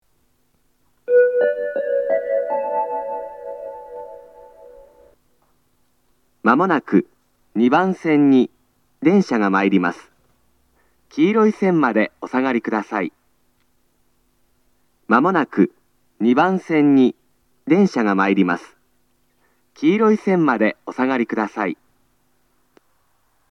（男性）
接近放送
鳴動開始は到着約3分前です。